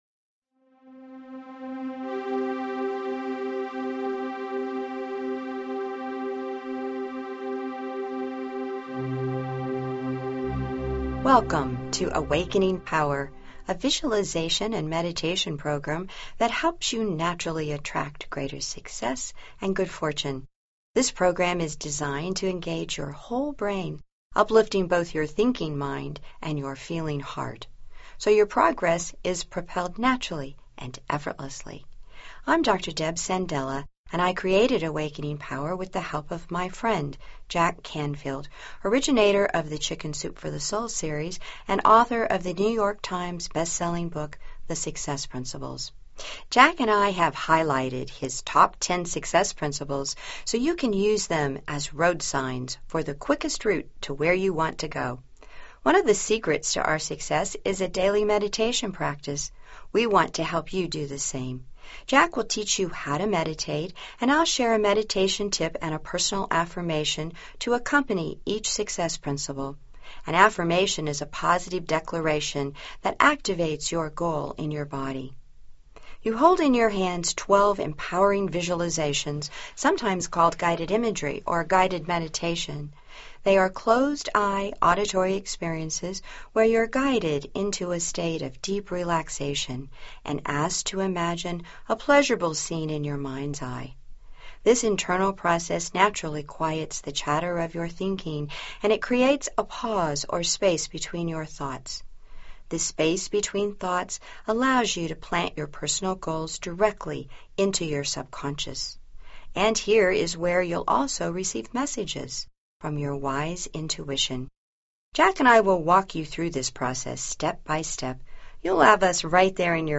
To help you get started, I have a great free gift to give you today: “Awakening Power” – CD 1 This 72-minute audio session will teach you the science of visualization, give you the basics of meditation, and then guide you through the powerful “Inquire Within” meditation so you can use it to accelerate your journey to success!